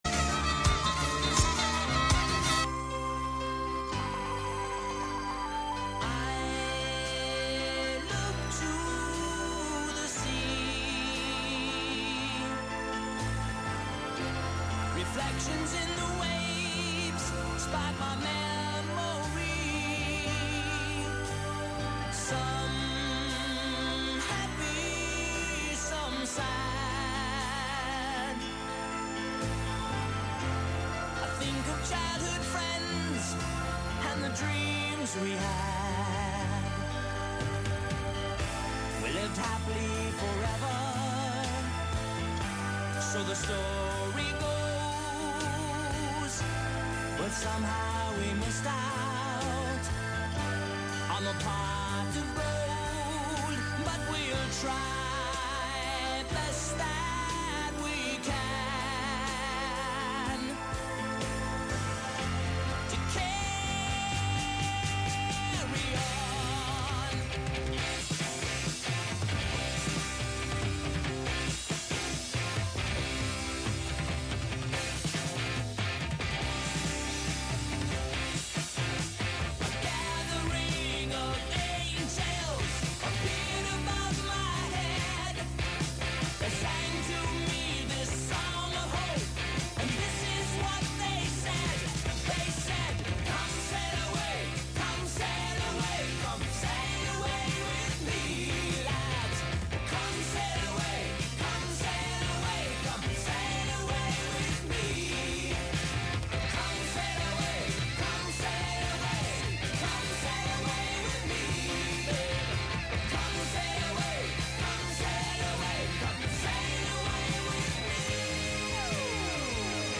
Live from Brooklyn, NY